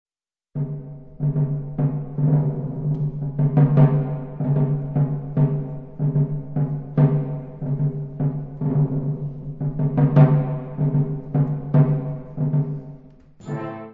Instrumentation Ha (concert/wind band)